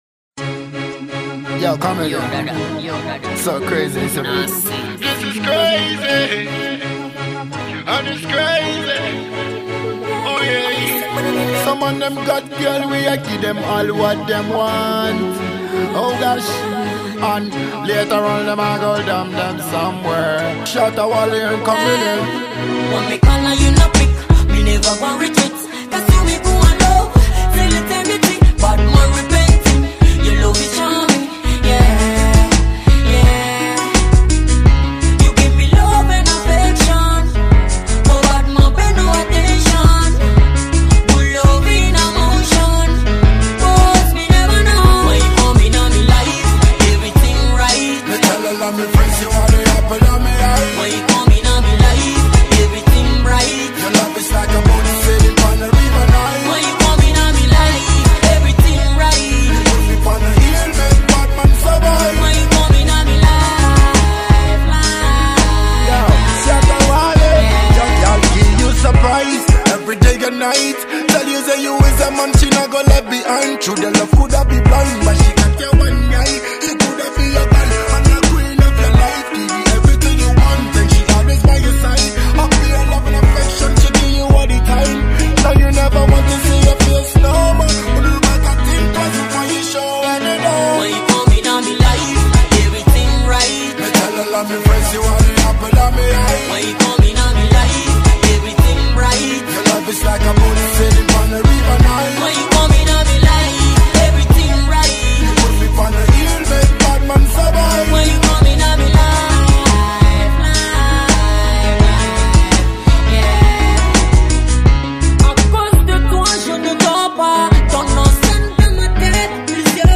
silky smooth voice
frantic delivery